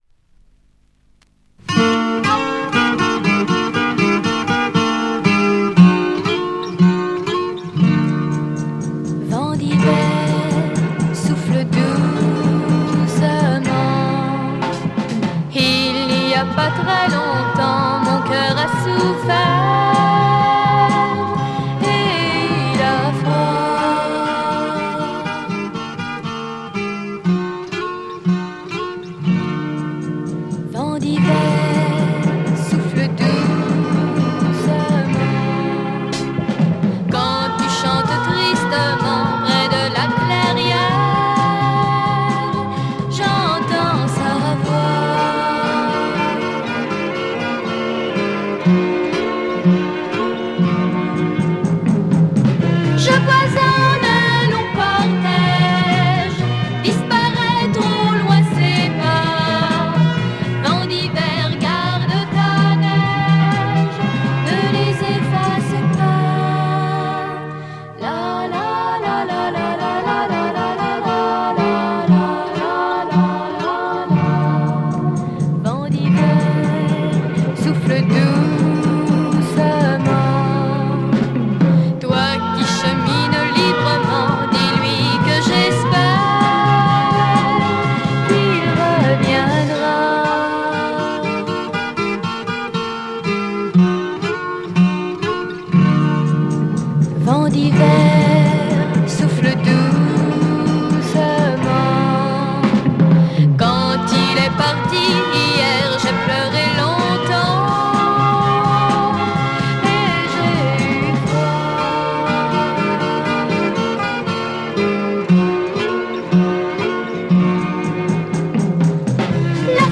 Killer French lolita psych folk 2Siders!